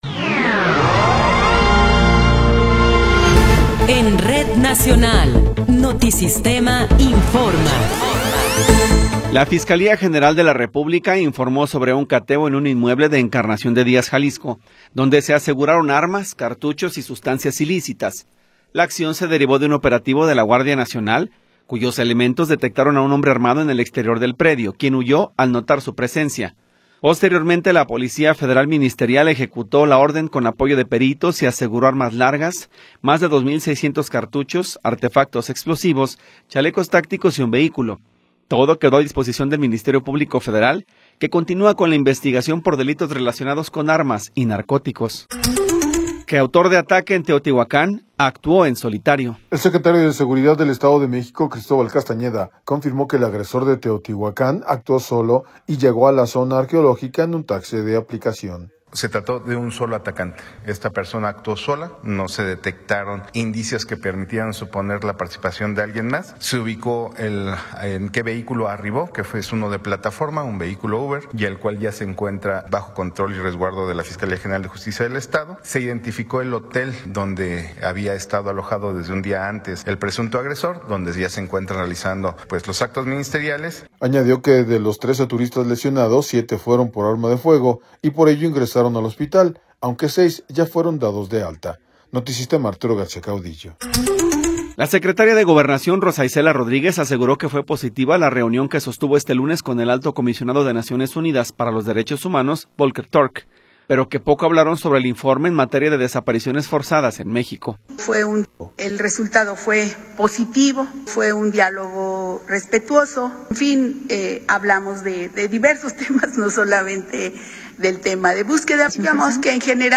Noticiero 11 hrs. – 21 de Abril de 2026
Resumen informativo Notisistema, la mejor y más completa información cada hora en la hora.